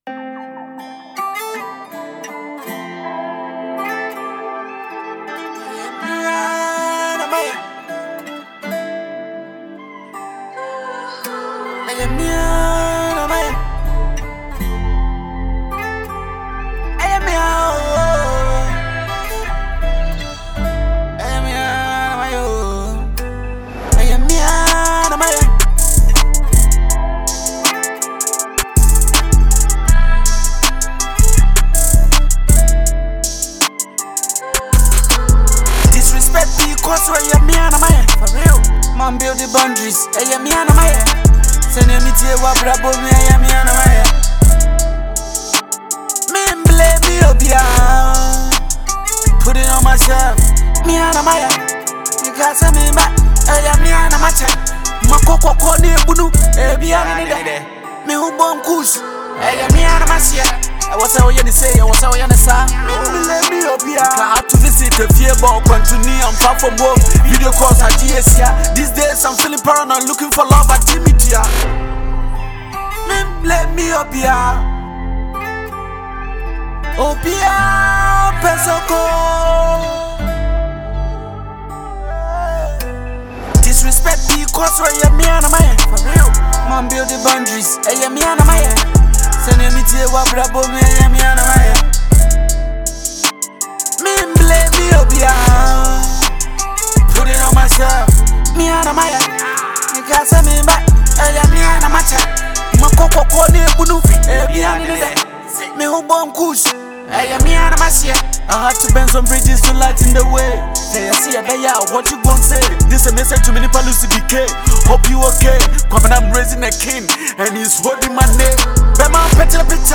Genre: Hip-Hop / Conscious Rap